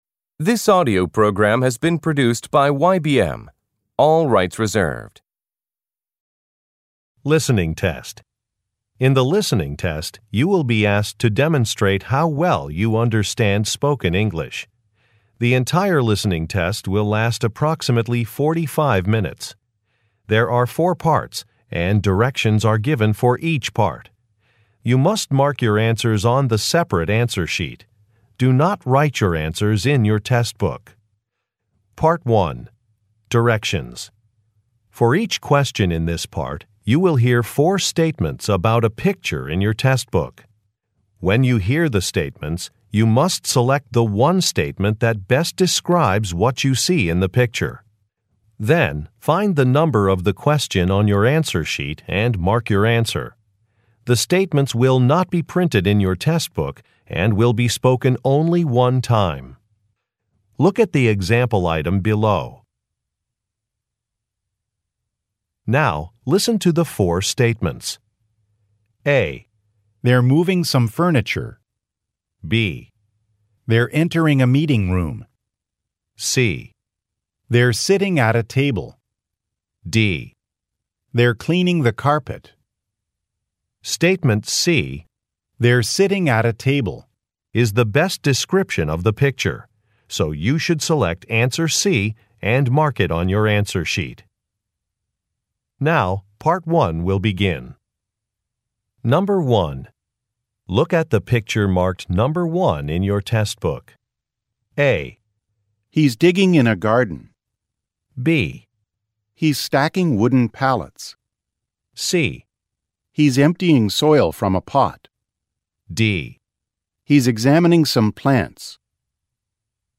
PART 1 Directions : For each question in this part, you will hear four statements about a picture in your test book.
The statements will not be printed in your test book and will be spoken only one time.